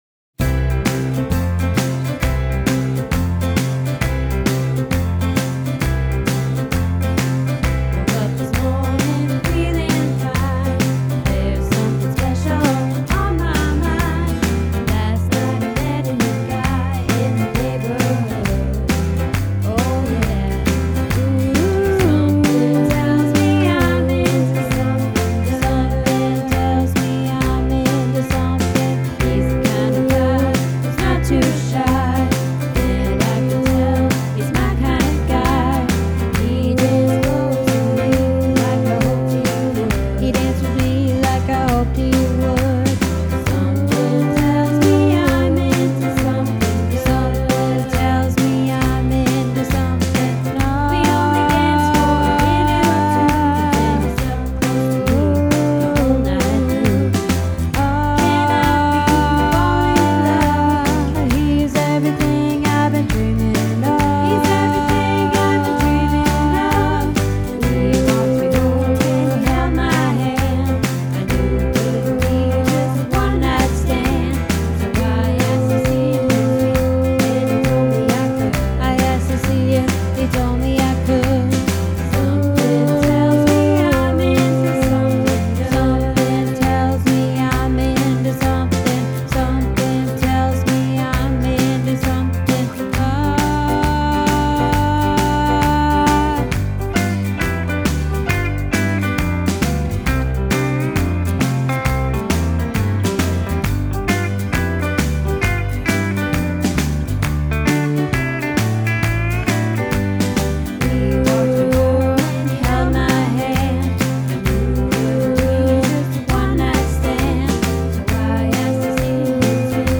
Into Something Good - Bass